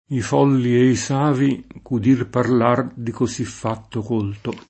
i f0lli e i S#vi k ud&r parl#r di koSi ff#tto k1lto]; da l’empio colto che ’l mondo sedusse [da ll %mpLo k1lto ke l m1ndo Sed2SSe]: esempi rimasti senza séguito nella tradiz. lett., per il s. m., a differenza dell’agg. colto